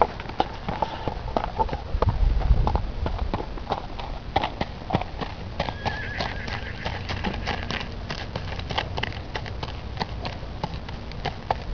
Oder wie hört sich die Gangart Schritt an?
Bei der Tondatei passend zum letzten Bild hat offensichtlich ein Artgenosse auf der Wiese nebenan das Muster richtig erkannt und mit einem Gewieher geantwortet.
Abb. 08: Ein Reiter im Nebel. Wahrnehmungen: Geräusche der Hufe.